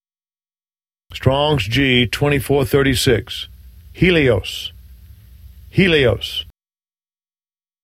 Phiên âm quốc tế: hē’-le-ōs Phiên âm Việt: hí-li-ốt